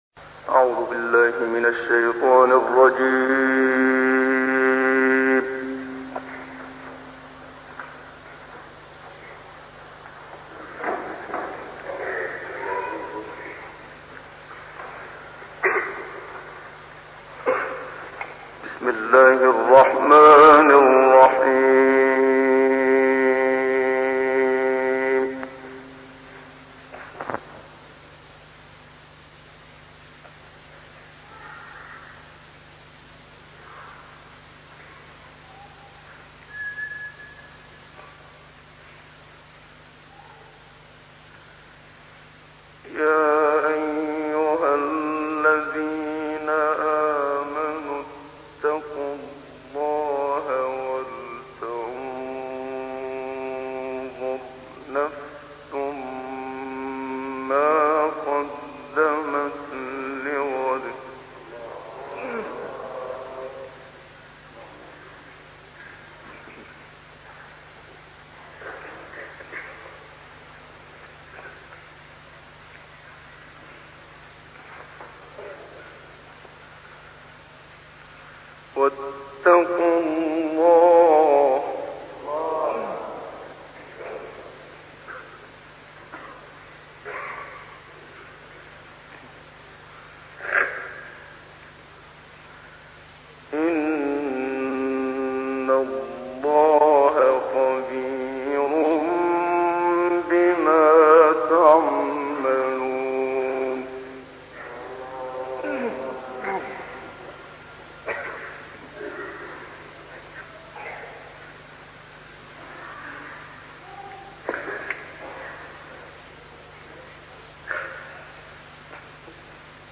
تلاوتي زيبا و دلنشين از استاد محمد صديق منشاوي
تلاوتی زیبا و دلنشین از استاد محمد صدیق منشاوی